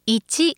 ホームページ作成で利用できる、さまざまな文章や単語を、プロナレーターがナレーション録音しています。
ナレーション：